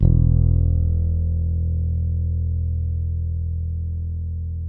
ALEM FING E1.wav